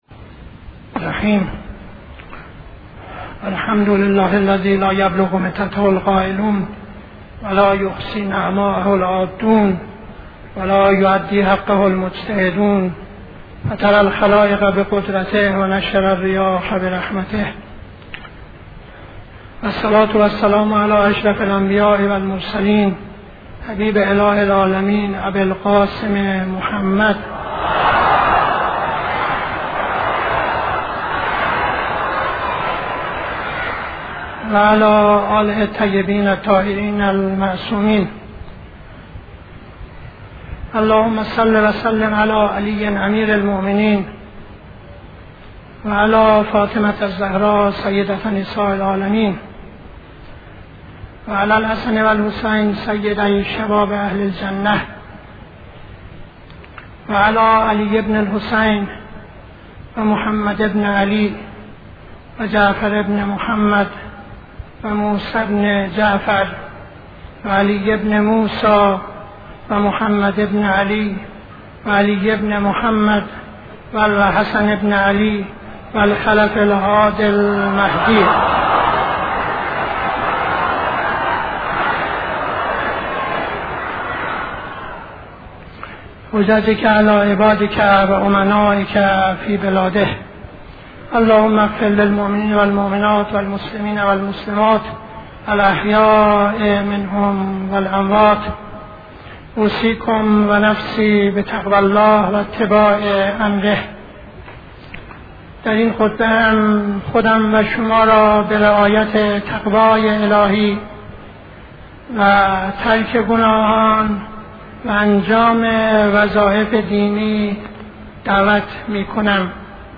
خطبه دوم نماز جمعه 19-09-72